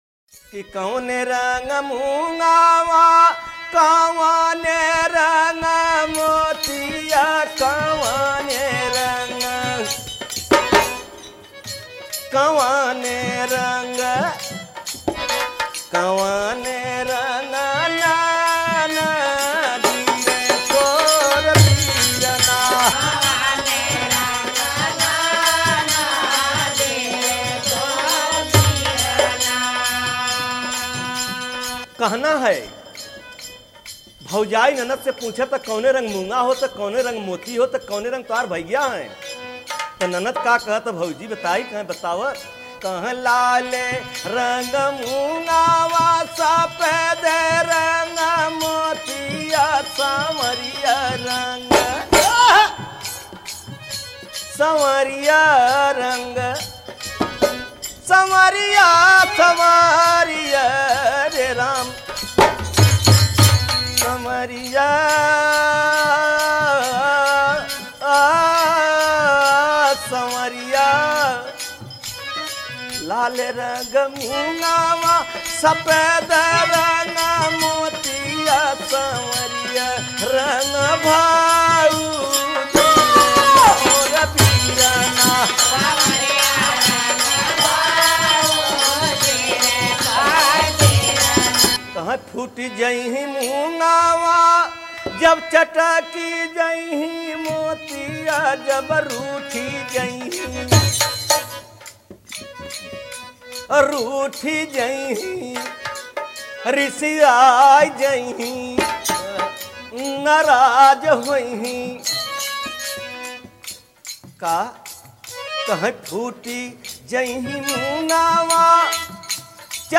Kajri